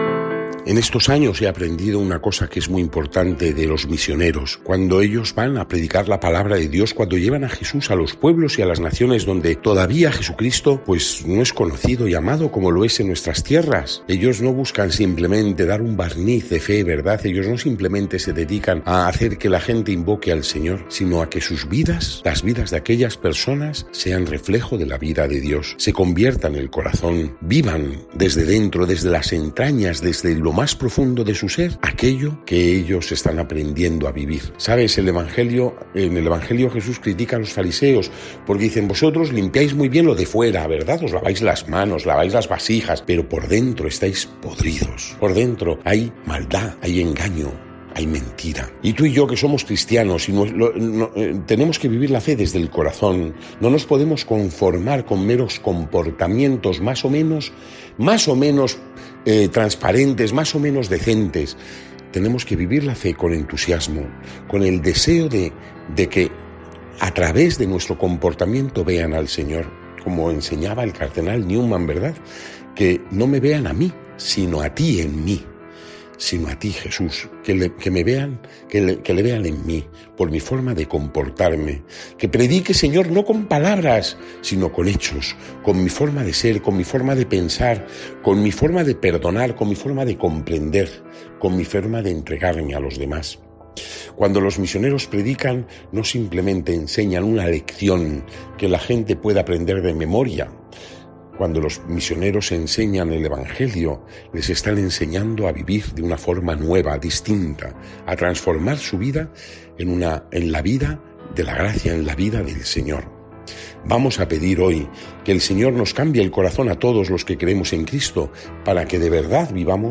Lectura del santo evangelio según san Lucas (11,29-32)